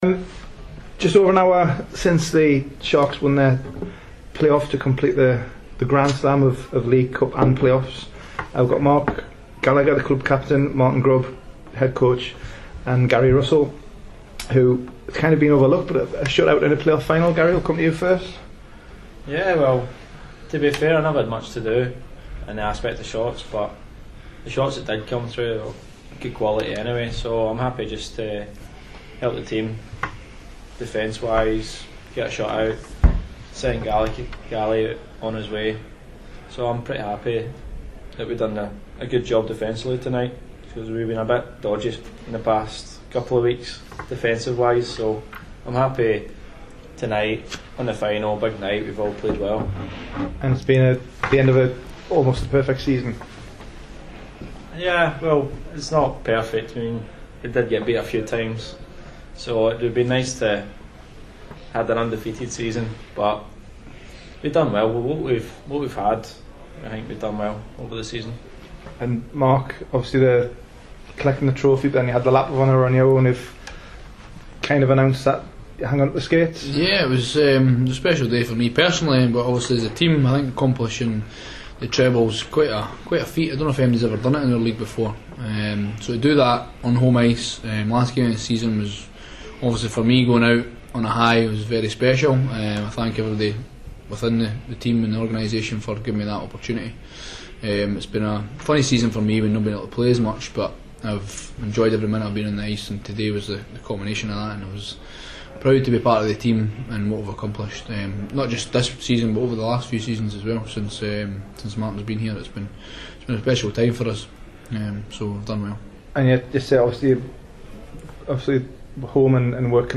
May 2014 - Playoff round table with Solway Sharks